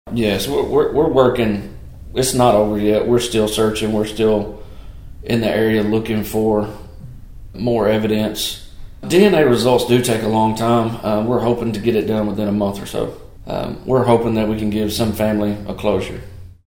Chief Amberg said it was their hope that the DNA analysis will reveal the identity of the individual.(AUDIO)